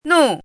汉字“怒”的拼音是：nù。
“怒”读音
怒字注音：ㄋㄨˋ
国际音标：nu˥˧
nù.mp3